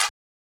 Klap [Virus].wav